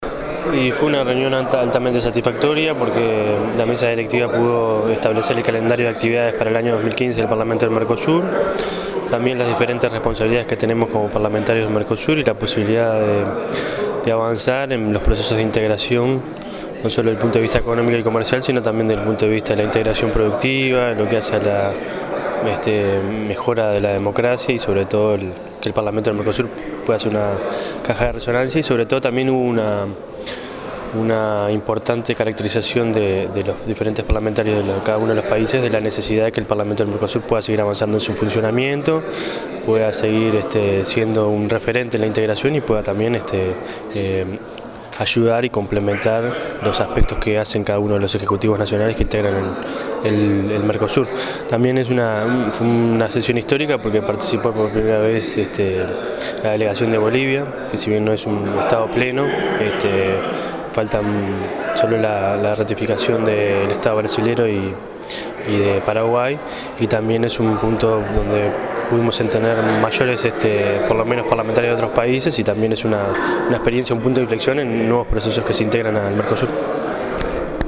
Parlamentario Daniel Caggiani, Vicepresidente por Uruguay
daniel-caggiani---mesa-directiva.mp3